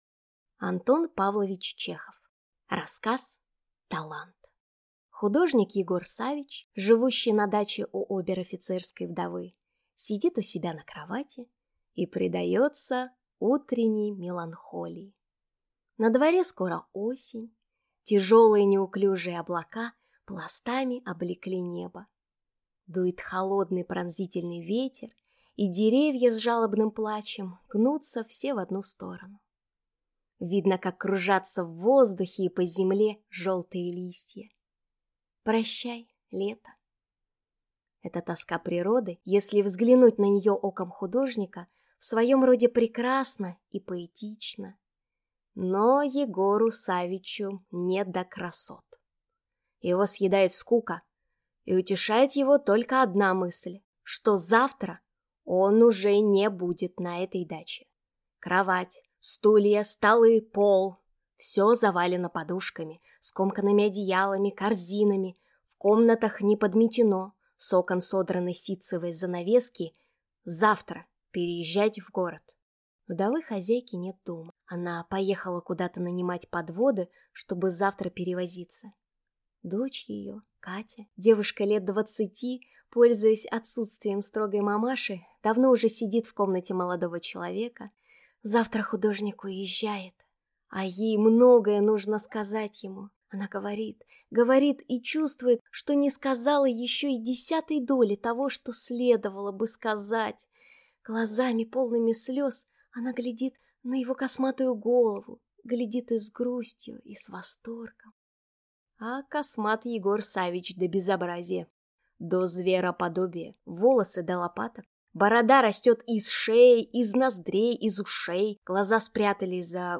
Аудиокнига Талант | Библиотека аудиокниг